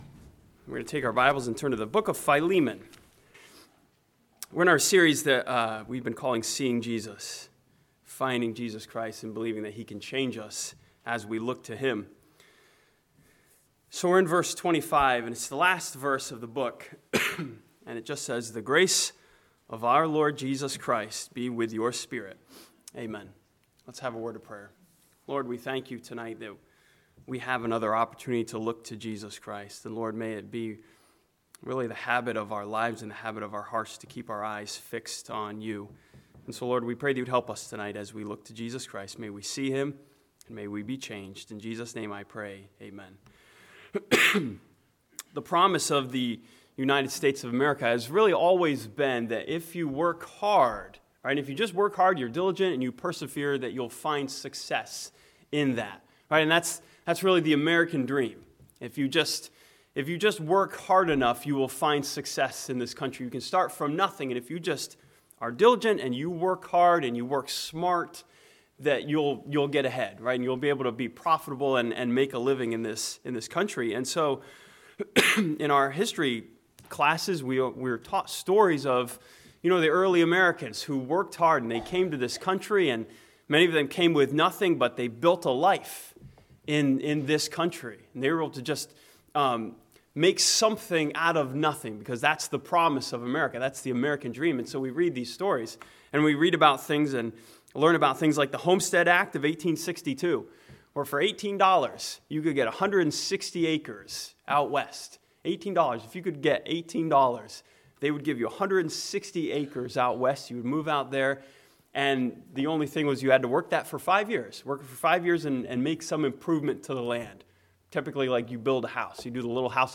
This sermon sees Jesus as the Gracious Lord who gives us grace that we can then give to others.